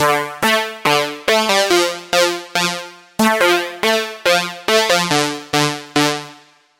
标签： 141 bpm Trance Loops Synth Loops 1.15 MB wav Key : Unknown
声道立体声